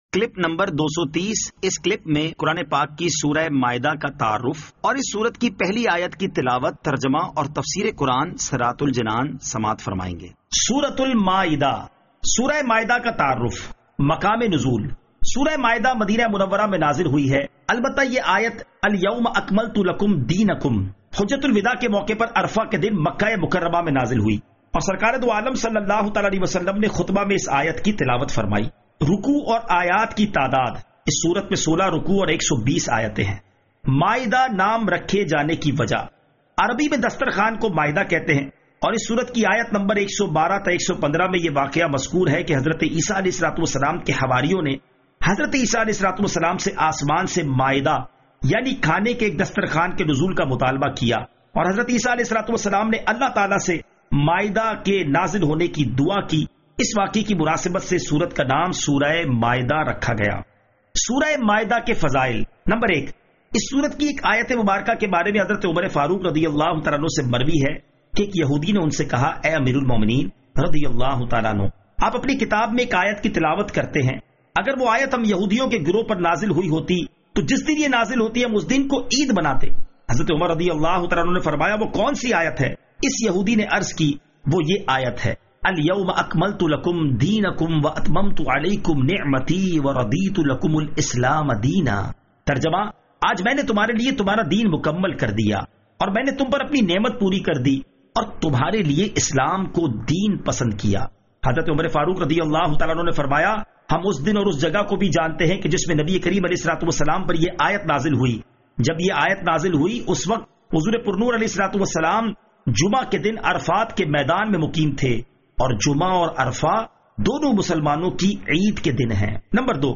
Surah Al-Maidah Ayat 01 To 01 Tilawat , Tarjama , Tafseer